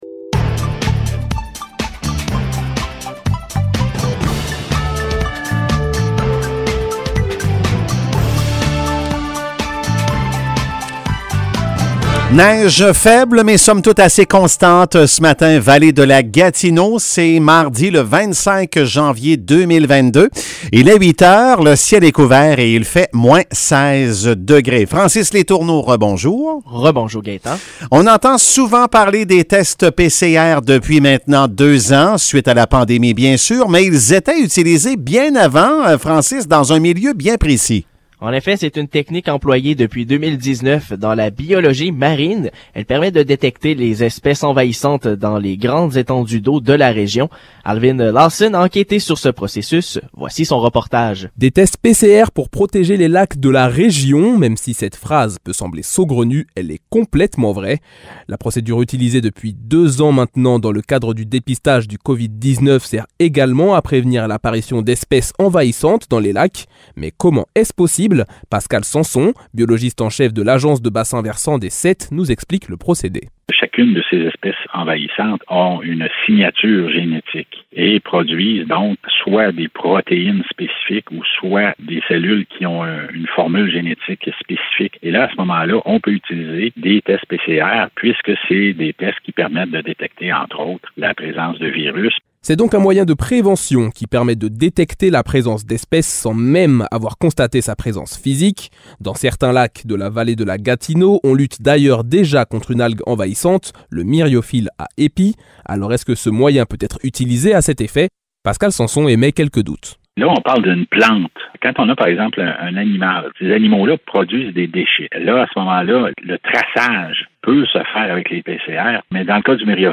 Nouvelles locales - 25 janvier 2022 - 8 h